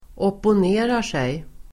Uttal: [åpon'e:rar_sej]